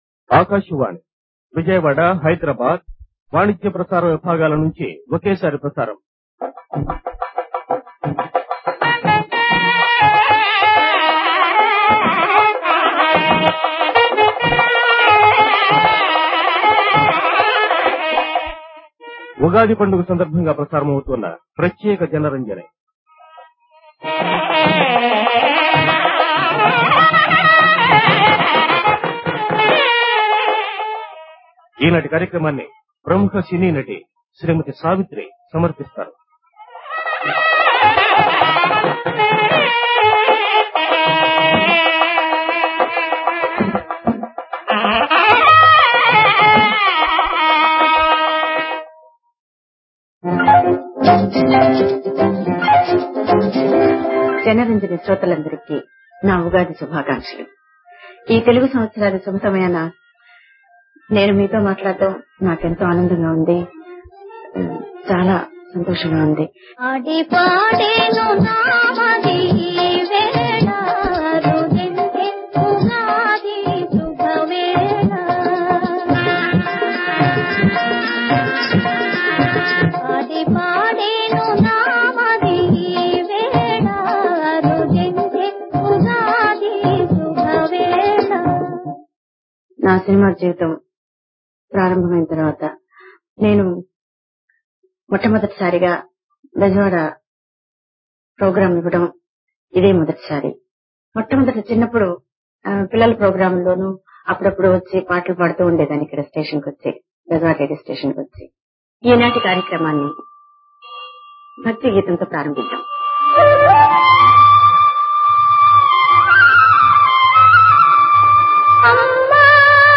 మహానటి సావిత్రి సమర్పించిన ఈ ప్రత్యేక జనరంజని కార్యక్రమం, ఆకాశవాణి విజయవాడ, హైదరాబాద్ కేంద్రాల వివిధభారతి లో 28 మార్చ్ 1979 ఉగాది రోజున ప్రసారమయింది. బహుశా ఇదే ఆమె ఆఖరి రేడియో కార్యక్రమం.